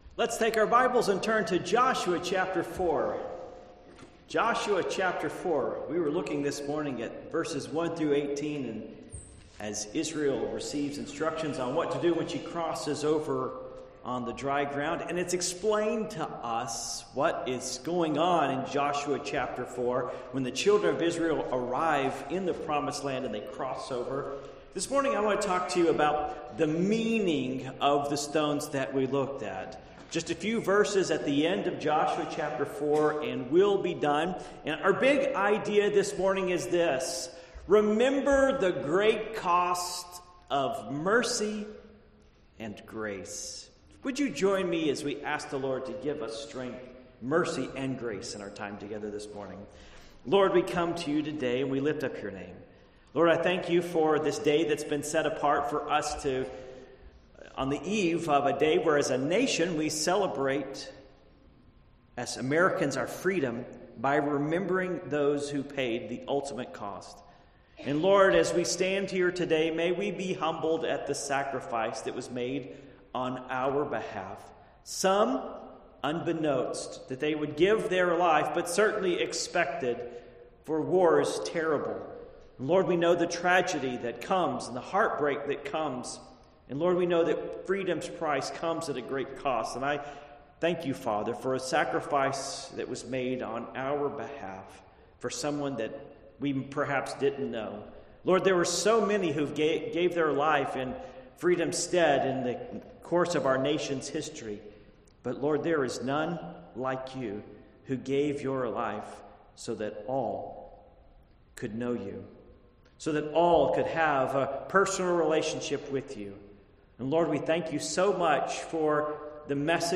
Passage: Joshua 4:19-24 Service Type: Morning Worship